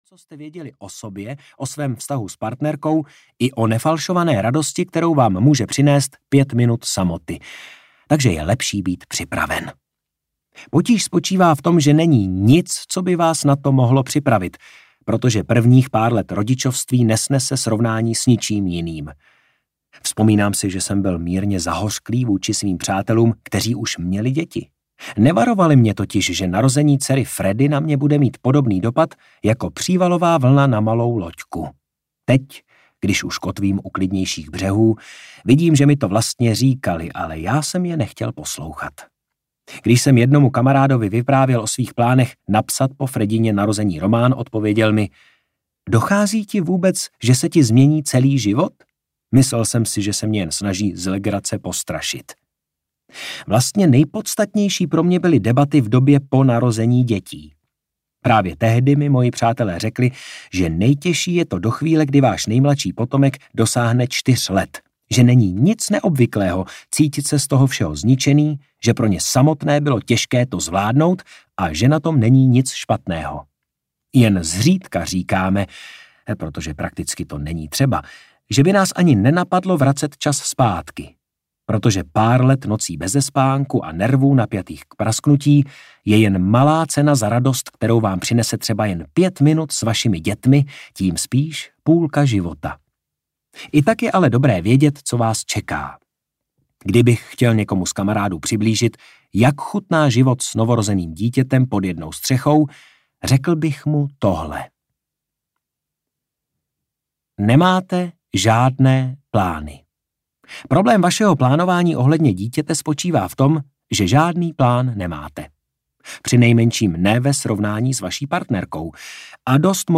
Žádný sex a žádný spánek audiokniha
Ukázka z knihy